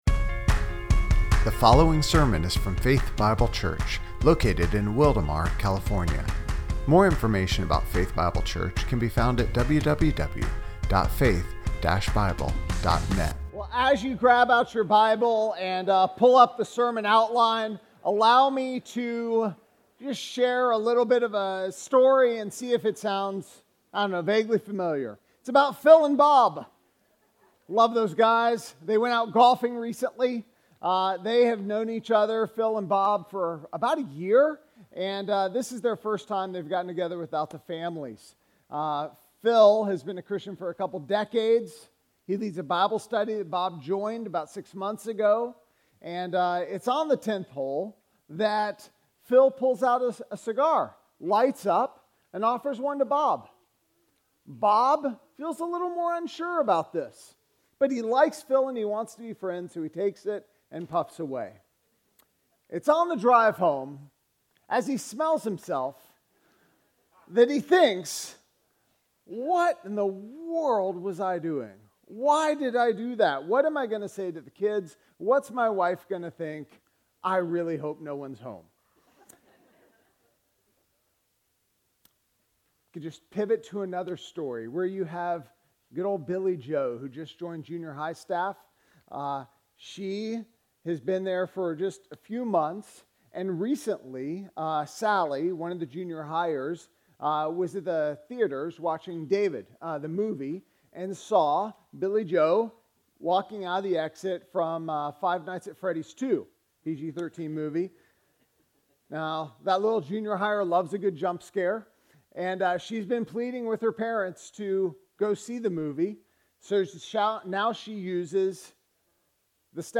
Faith Bible Church Sermons